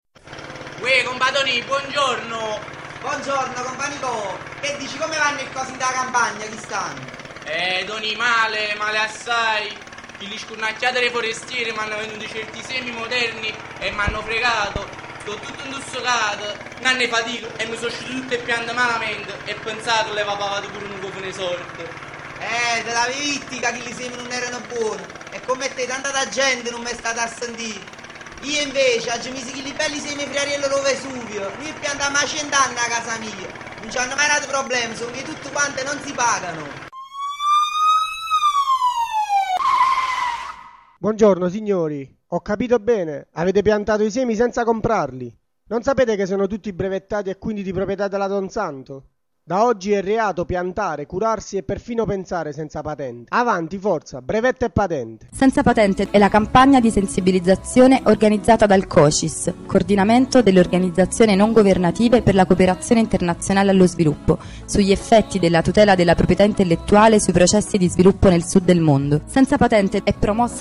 Ascolta lo spot radiofonico realizzato dalla N:EA per la campagna Senza Patente e trasmesso dalle radio locali campane